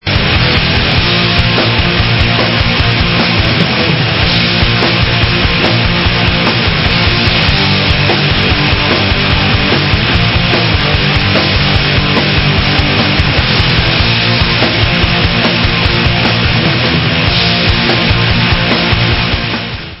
+ 15 YEARS OF SATANIC BLACK METAL // 2008 ALBUM